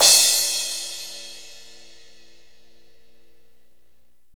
Index of /90_sSampleCDs/Northstar - Drumscapes Roland/CYM_Cymbals 2/CYM_F_T Cyms x